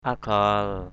/a-ɡ͡ɣal/ (d.) lá buông = feuille de palmier. palm leaf. akhar dalam agal aAR dl’ agL chữ trên lá buông = letters on palm leaf. agal tapuk agL tp~K [Bkt.]...